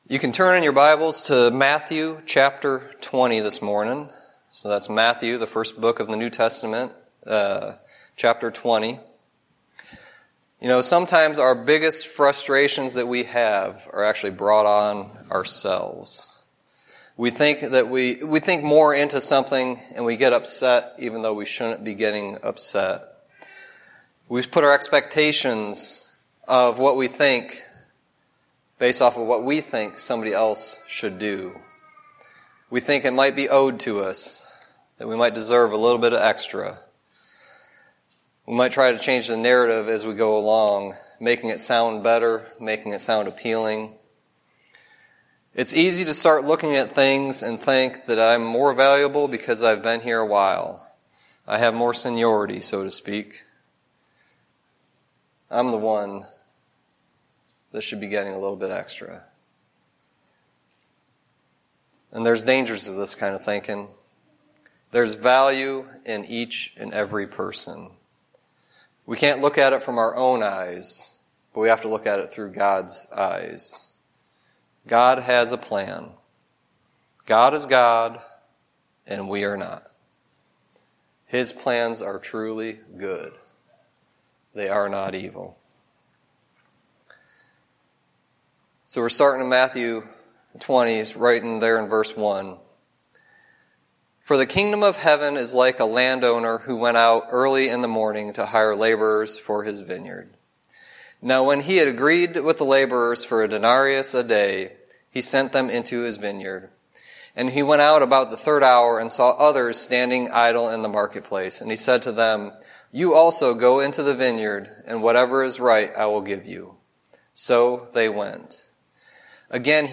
Sermon messages available online.